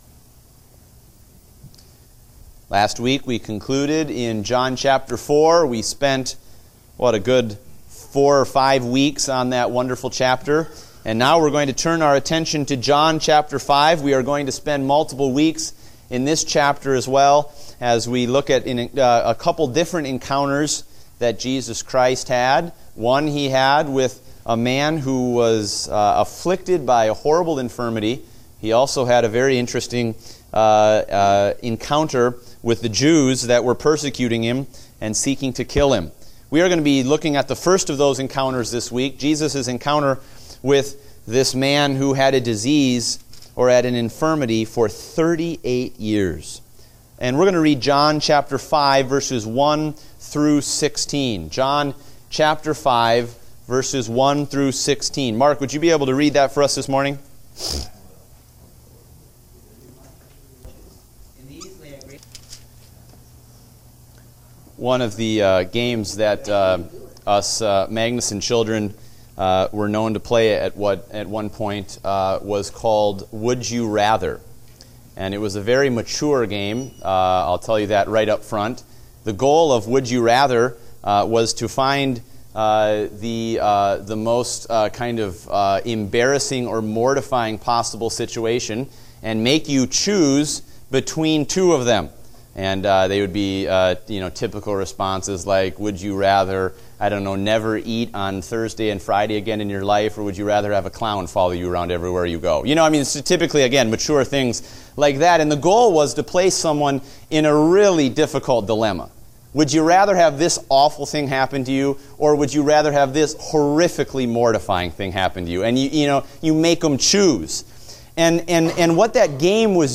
Date: July 17, 2016 (Adult Sunday School)